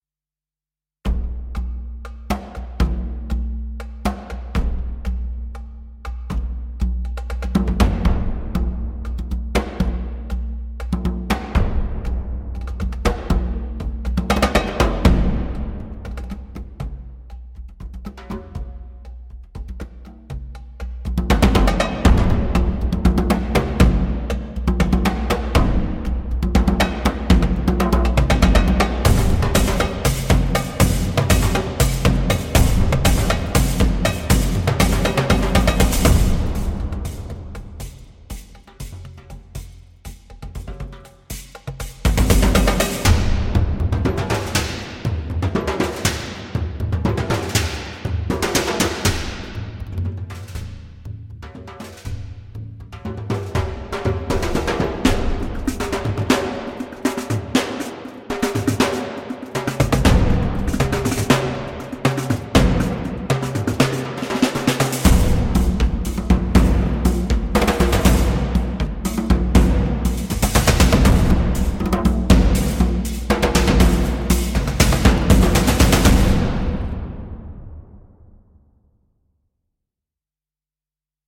Bucket Drums (Trommeln aus Kubstoffeimern)